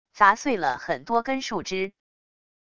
砸碎了很多根树枝wav音频